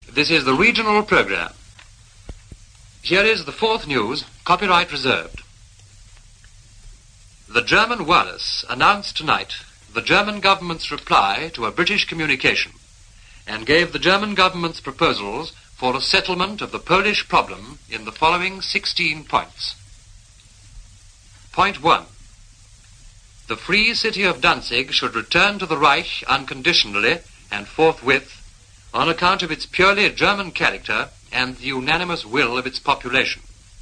Here’s Alvar Liddell on the Regional Programme, reporting on the German 16 Point Plan on August 31, 1939.